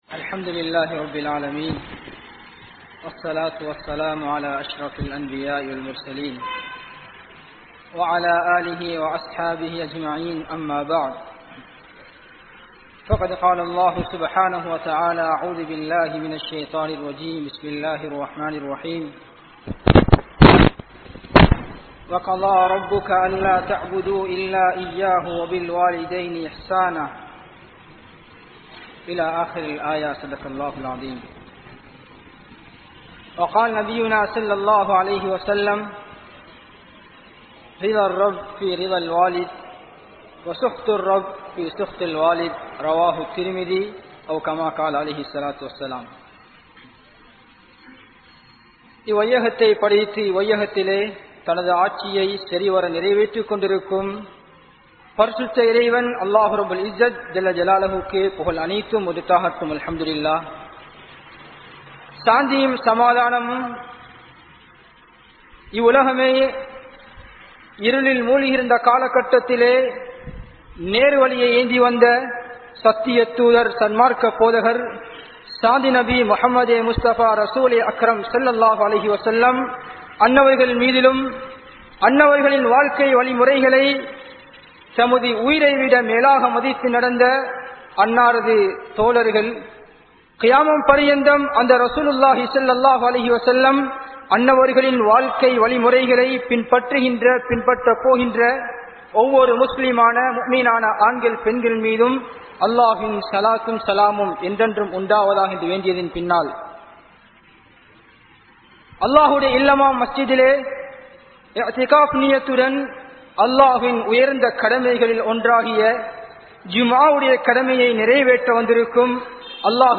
Pettroarhalukku Panividai Seiungal (பெற்றோர்களுக்கு பணிவிடை செய்யுங்கள்) | Audio Bayans | All Ceylon Muslim Youth Community | Addalaichenai